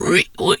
pig_2_hog_single_06.wav